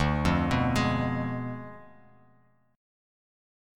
Ddim7 Chord
Listen to Ddim7 strummed